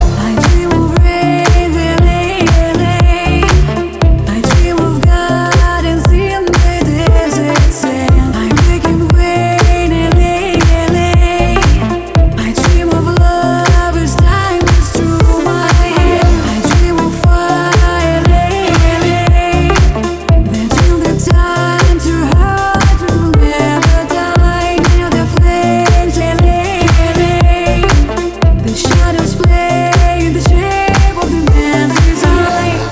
• Качество: 320, Stereo
женский вокал
deep house
Cover